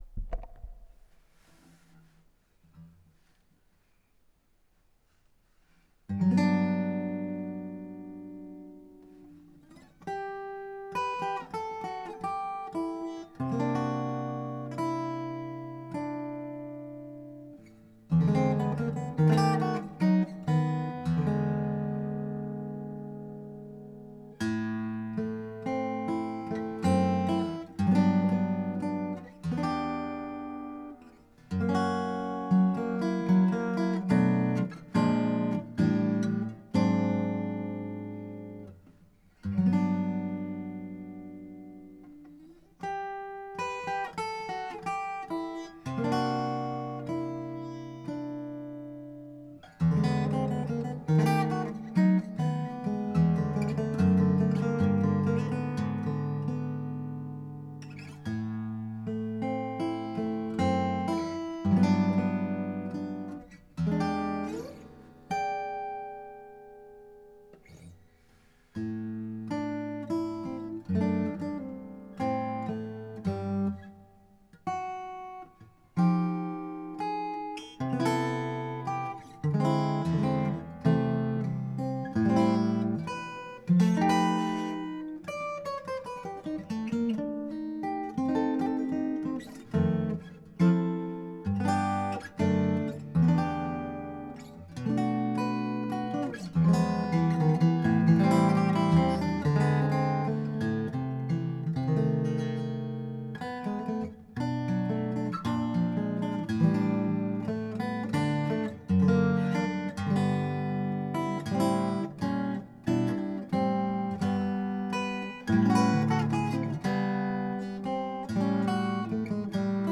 とにかくすばらしい音質です。
一応この機種の最高音質である、24ビット48kサンプリングで録音し、録音時に内蔵リバーブをかけてあります。
自宅の3階でドアを閉めて録音しましたが、2階のドアを閉める音が最後に収録されています。